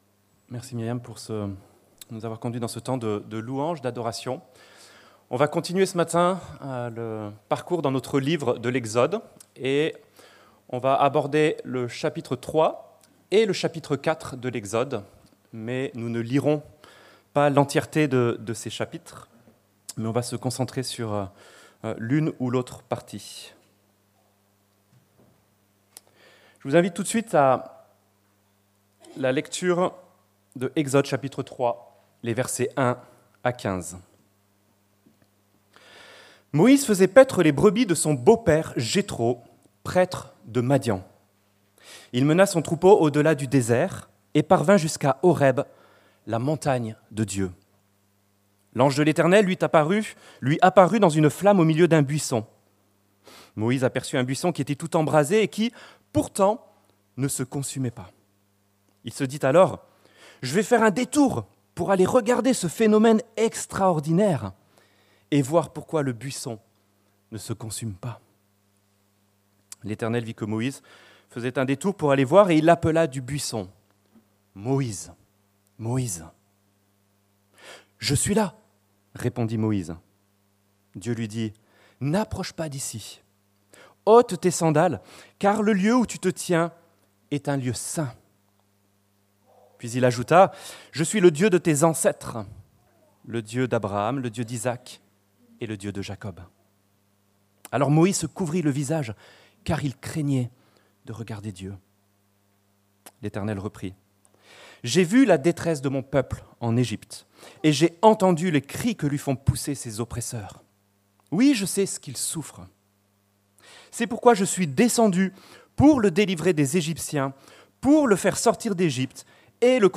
Quand Dieu appelle - Prédication de l'Eglise Protestante Evangélique de Crest sur le livre de l'Exode